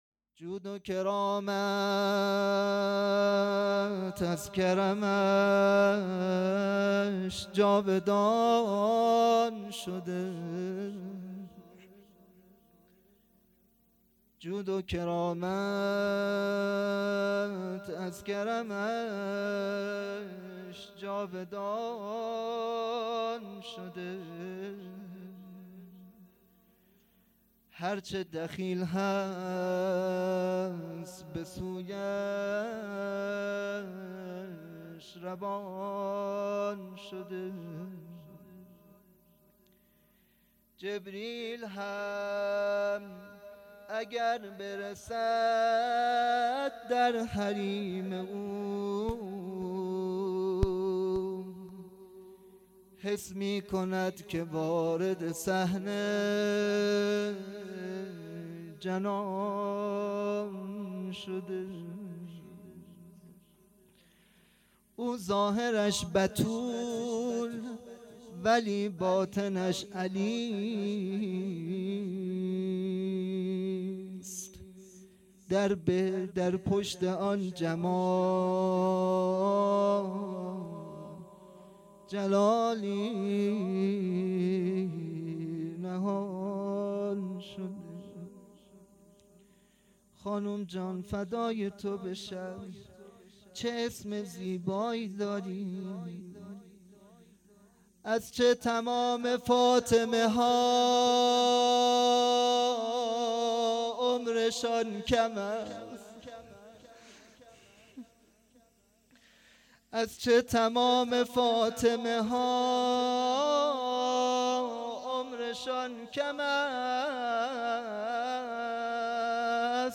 شهادت حضرت معصومه سلام الله علیها